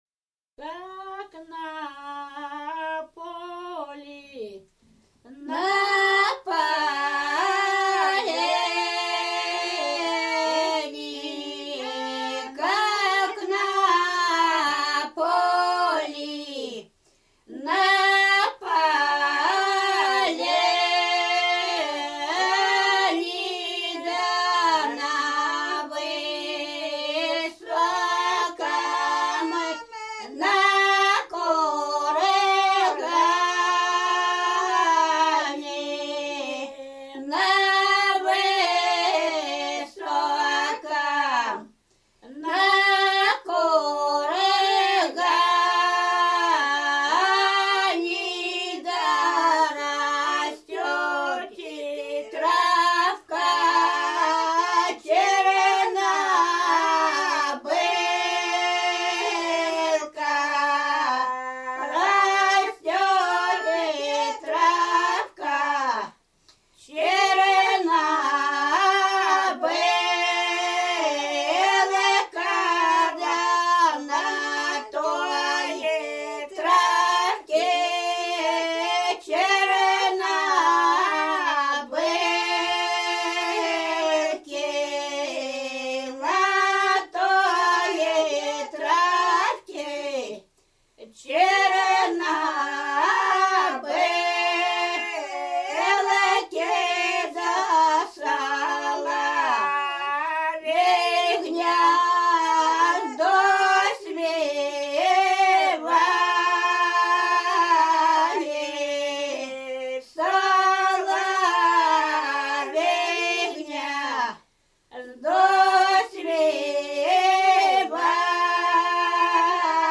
Рязань Кутуково «На поле, на поляне», русальская.